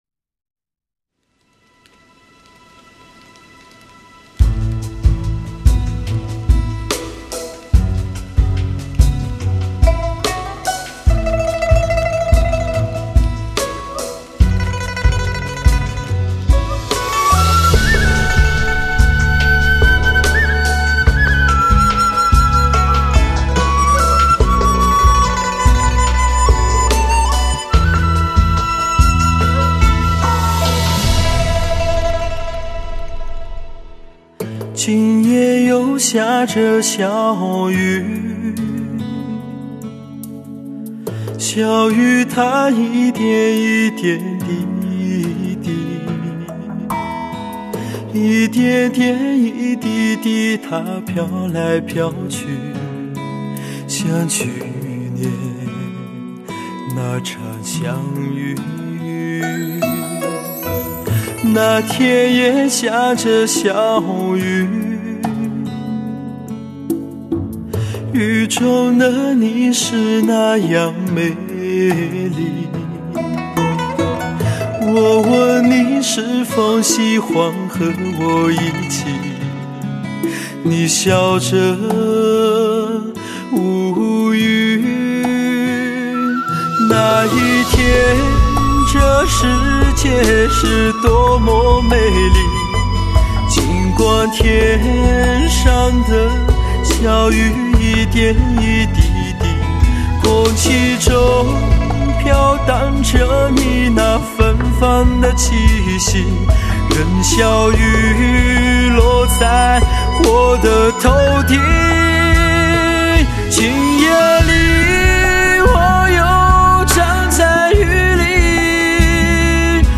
优雅的男声拨动心弦，琴音袅袅，朦胧的美编织着遐想，温柔地靠近，深情地离别……
绵绵的哀愁，化成了忧美舒展的旋律，柔柔的感伤，散发着无情不知多情的苦涩。